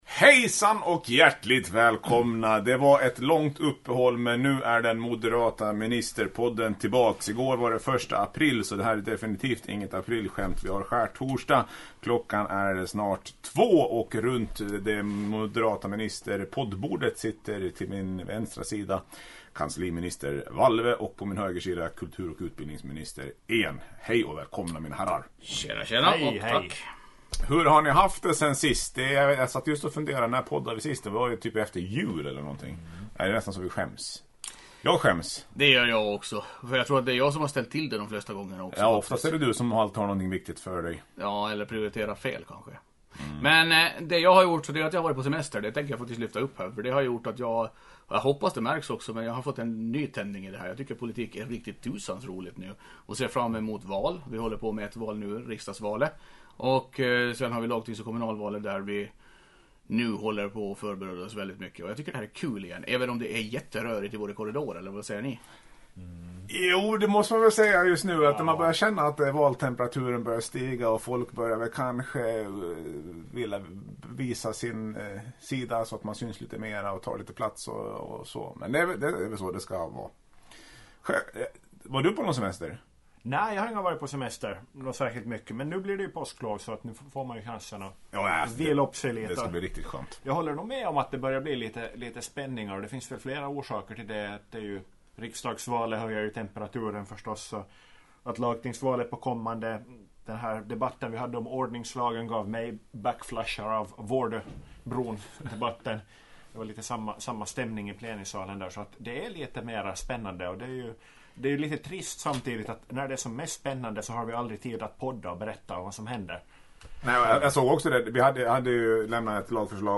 Efter ett alltför långt uppehåll är podden tillbaka. Jag tillsammans med mina moderata ministerkollegor spånar, funderar och pratar om veckorna som gått och dagarna som kommer.